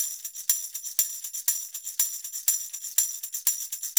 Pandereta_ ST 120_1.wav